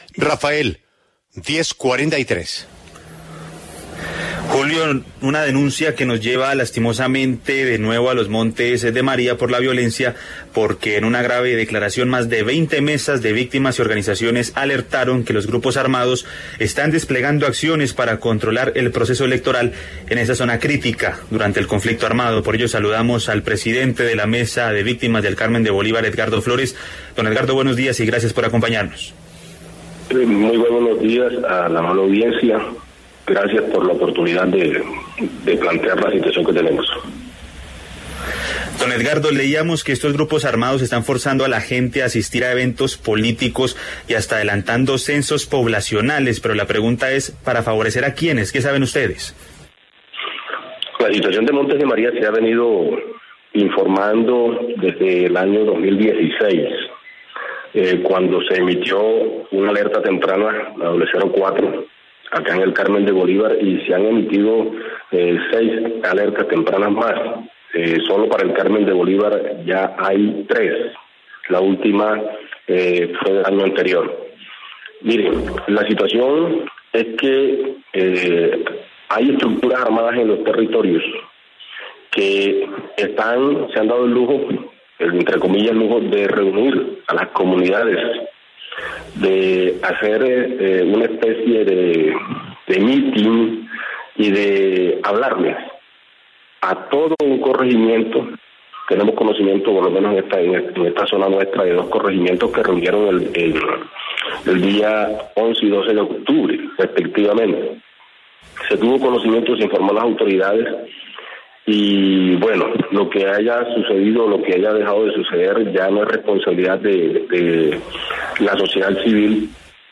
En entrevista con La W denunciaron intimidaciones de grupos armados en la zona.